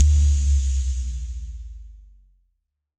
Bass Power On Club Synth.wav